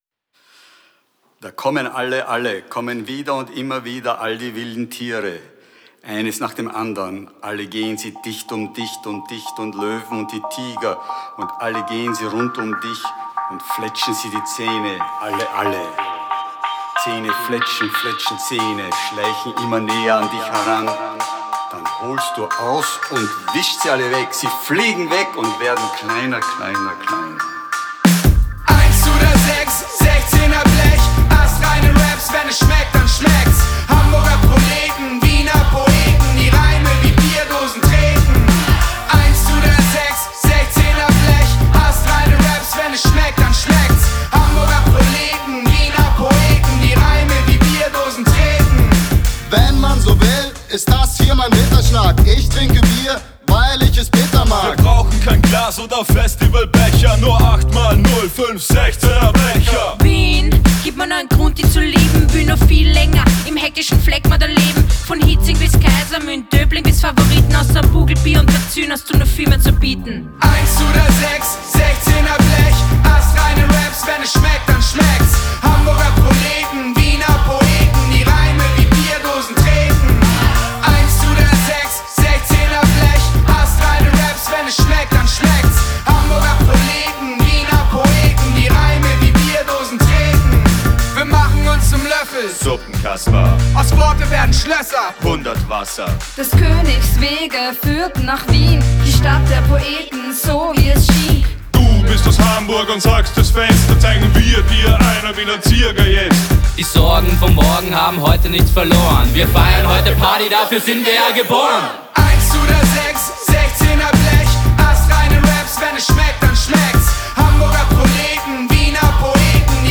vocals
beat
wurde in der schule für dichtung in wien aufgenommen